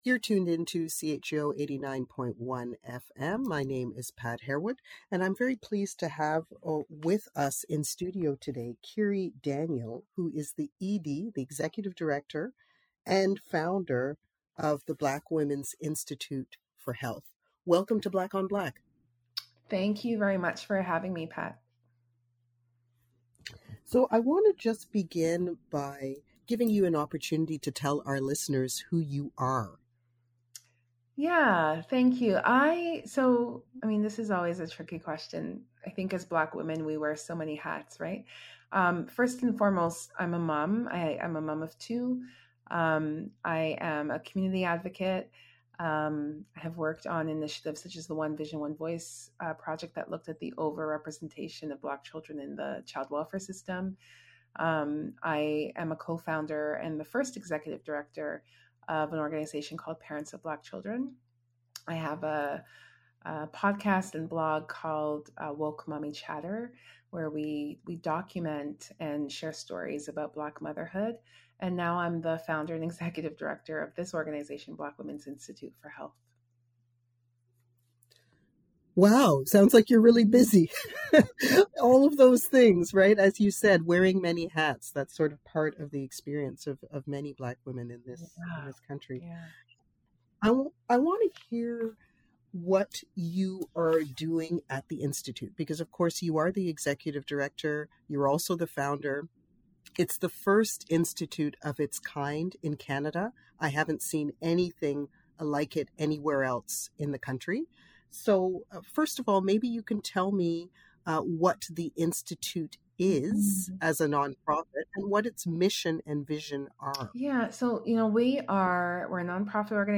CBC Ottawa Morning Radio